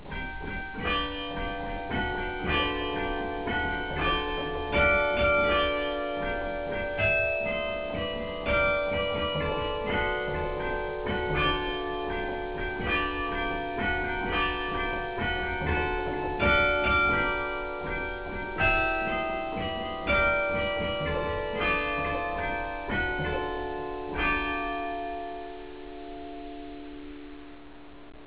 CARILLON BERGUES
Le carillon du beffroi de Bergues - Les Ritournelles - La Demie
Een fraeye man ou un Mari complaisant marque les 1/2 heures.
X Ecouter l'ancienne ritournelle de la demi-heure...